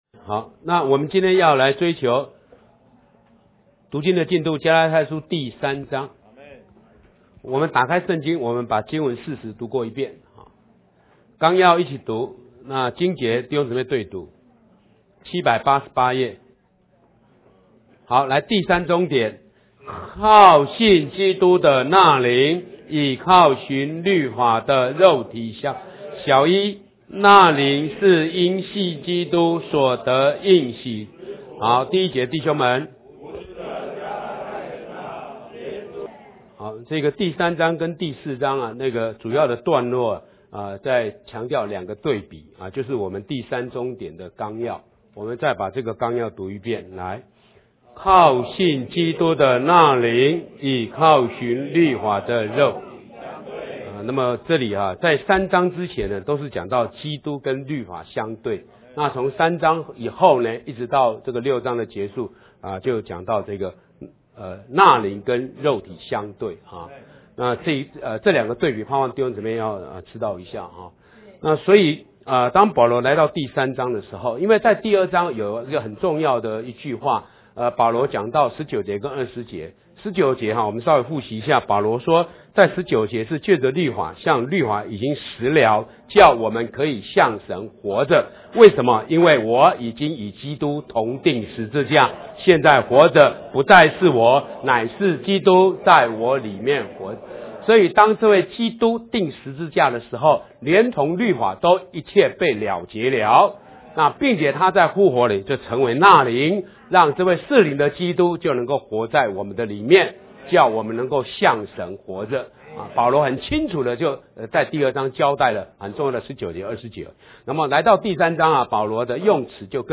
导读 + 读经指引 三 靠信基督的那灵与靠行律法的肉体相对 三1～四31 1 那灵是因信基督所得应许之福 三1～14 1 无知的加拉太人哪，耶稣基督钉十字架，已经活画在你们眼前，谁竟迷惑了你们？
加拉太书第3章___读经示范.mp3